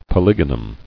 [po·lyg·o·num]